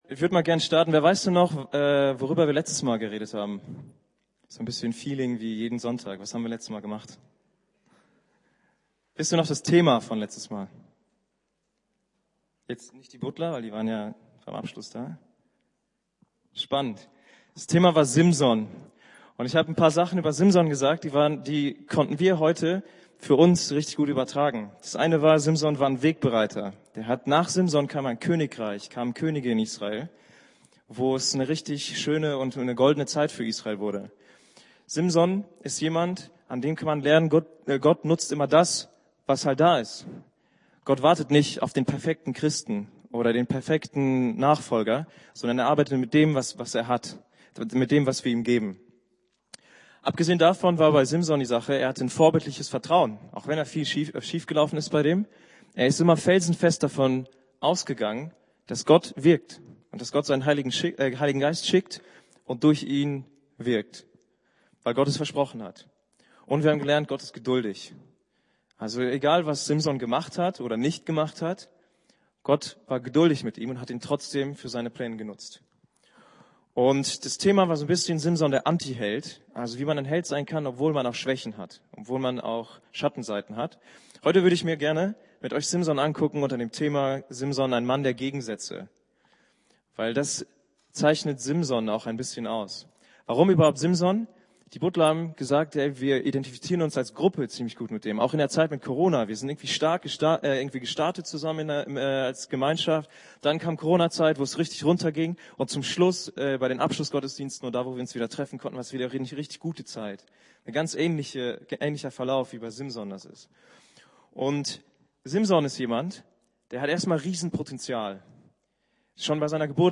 Jugendgottesdienst , Predigt Altes Testament